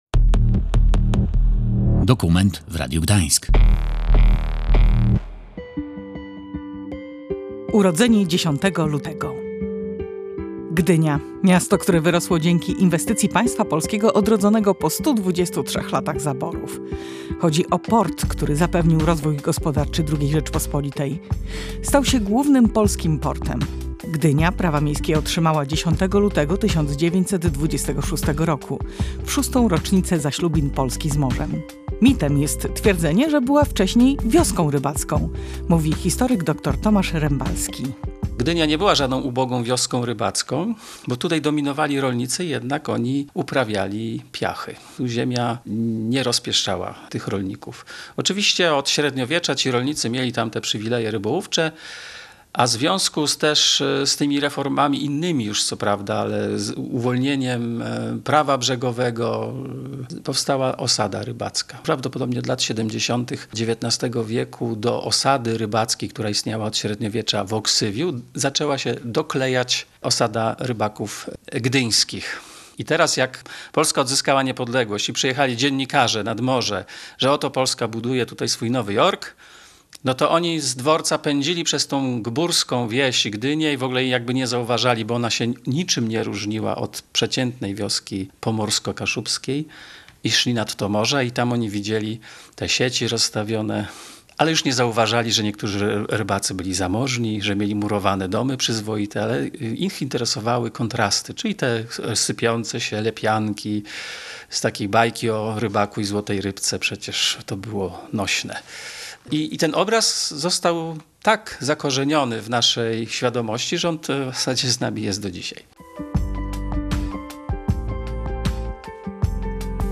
wykorzystane w audycji pochodzą z Archiwum Cyfrowego Muzeum Miasta Gdyni